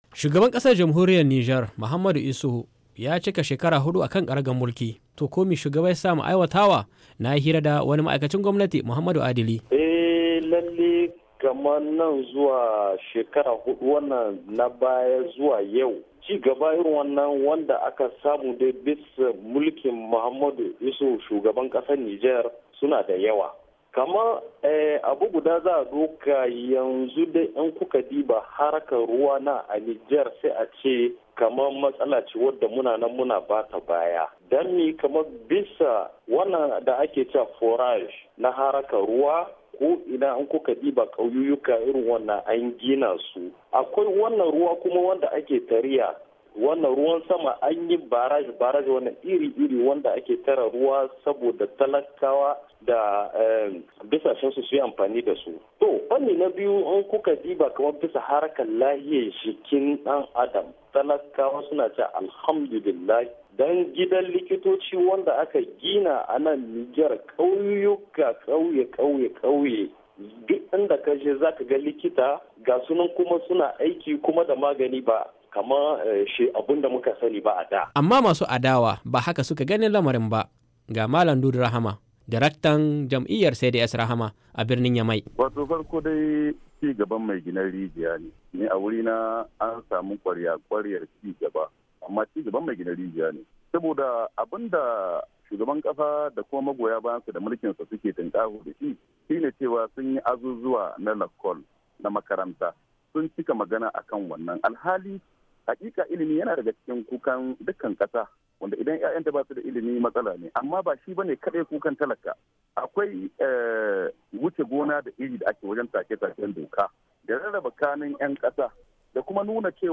Labari da Dumi-Duminsa
WASHINGTON D.C —